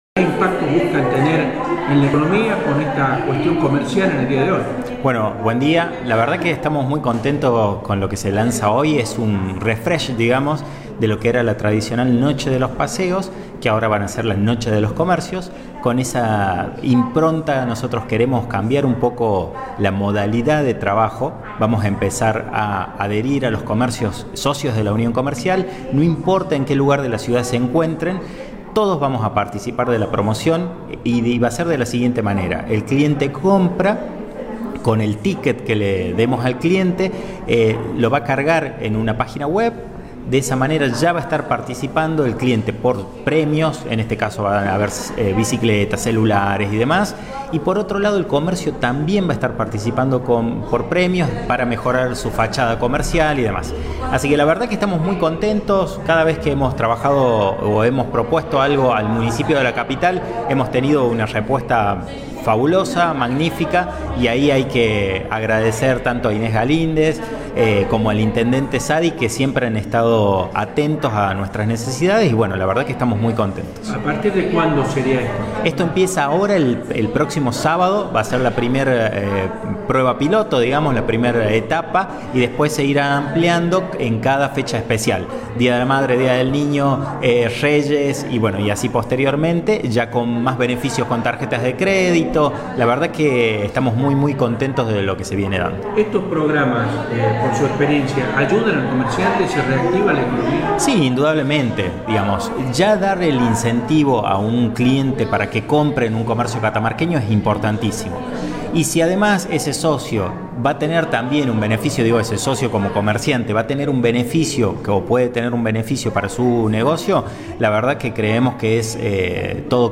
Entrevistas CityRadio CiTy Entrevistas